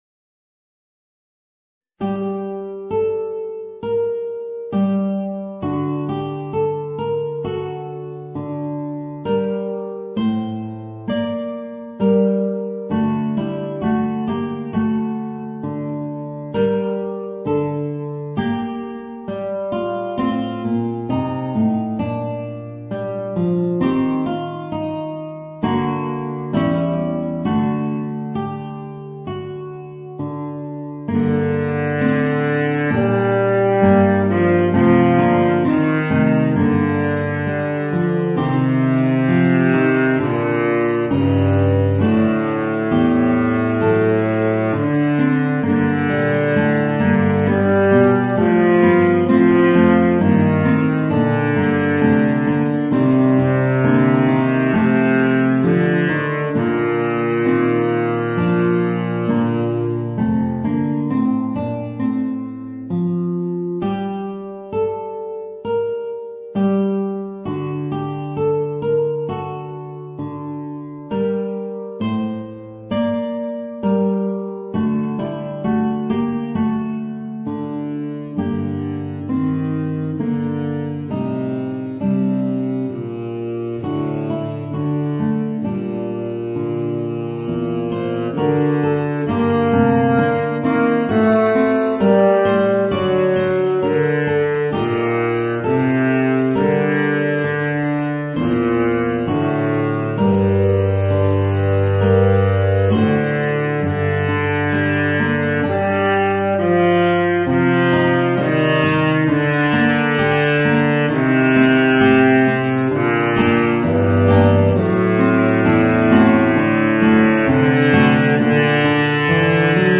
Voicing: Guitar and Baritone Saxophone